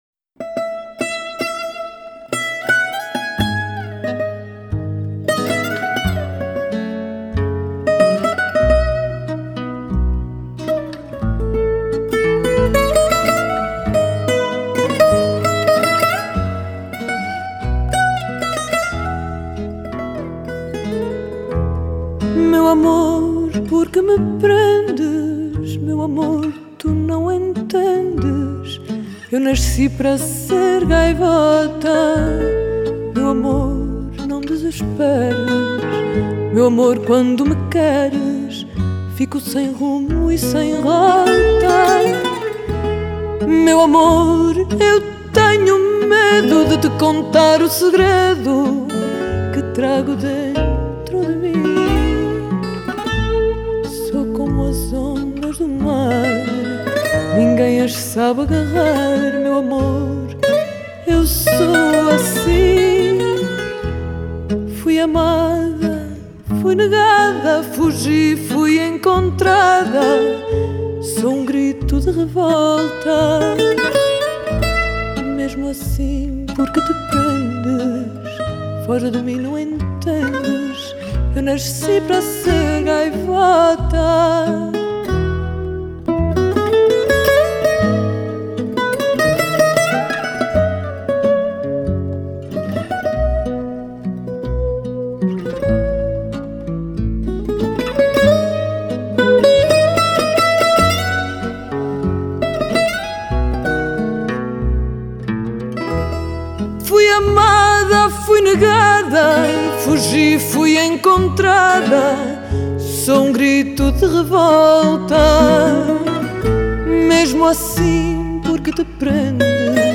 Genre: World, Fado